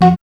4505L ORGCHD.wav